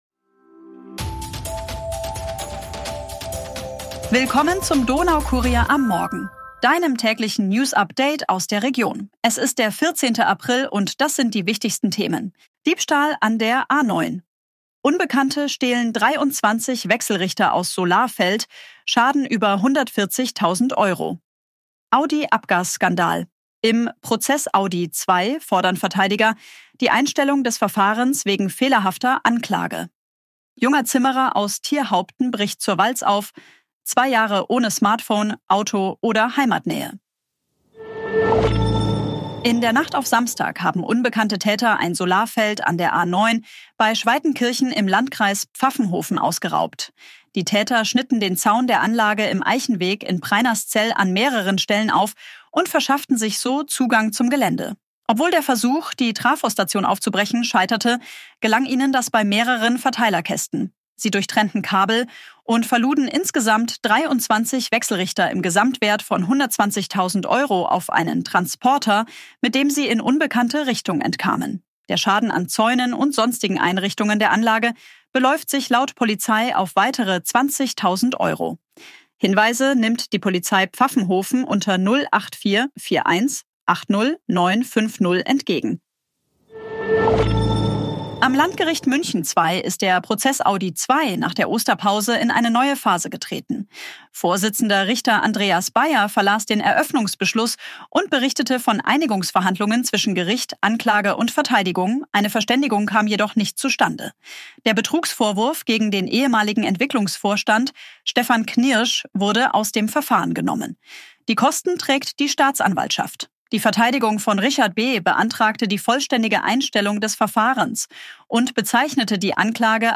Tägliche Nachrichten aus deiner Region